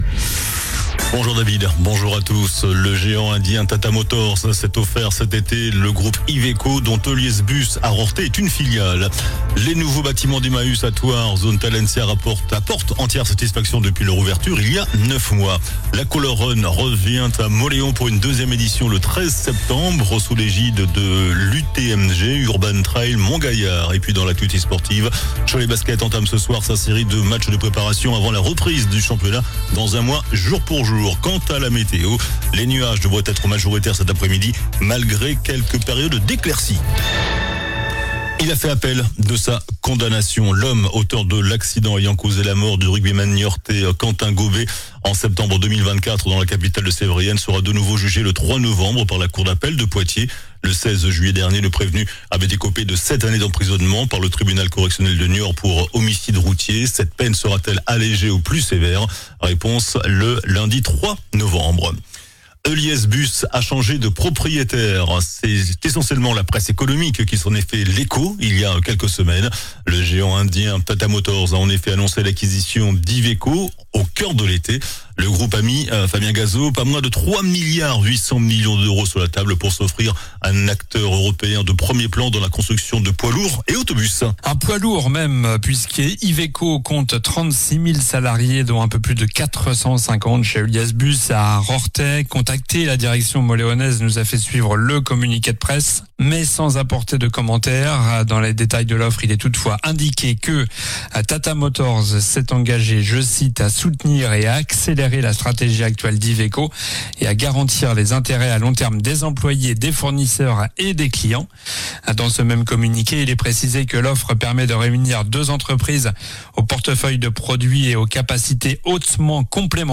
JOURNAL DU MERCREDI 27 AOÛT ( MIDI )